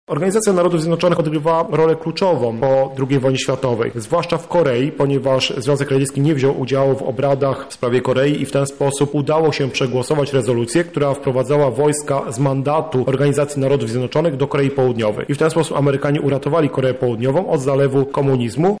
mówi historyk